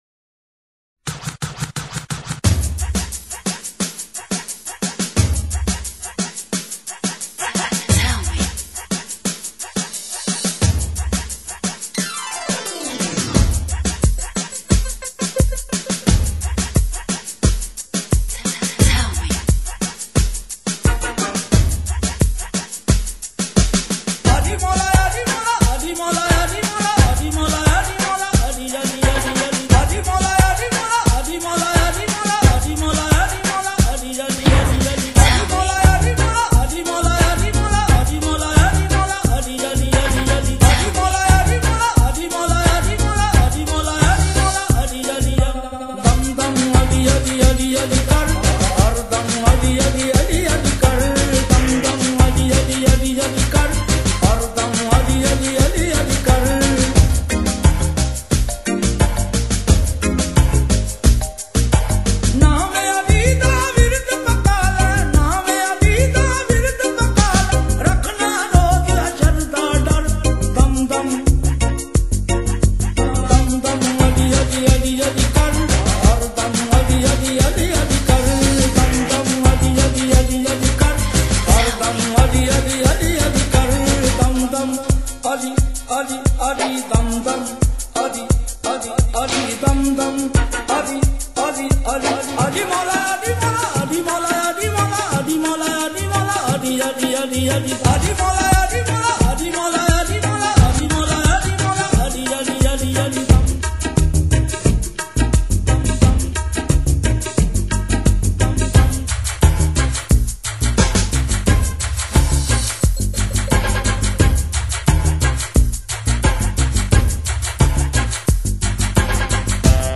Pakistani Qawwali MP3 Collection